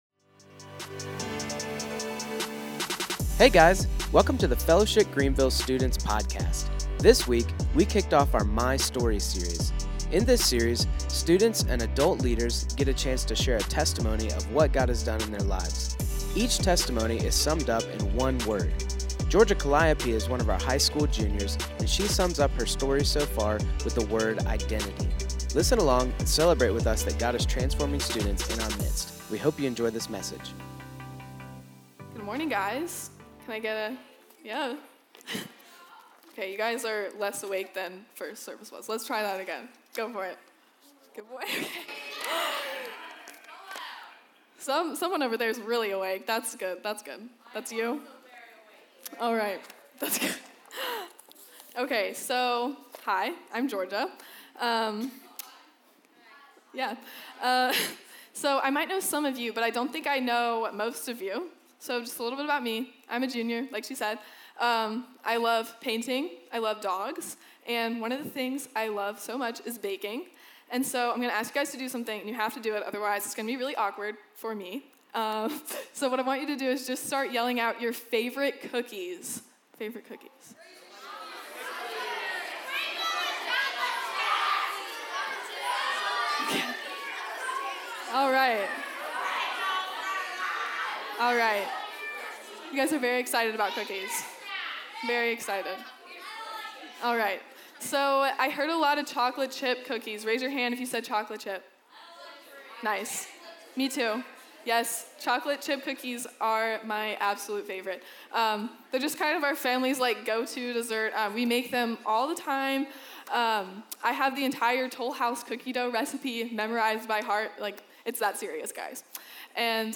In this My Story series, each communicator gives a testimony of what God has done in their lives and sums it all up in one Word.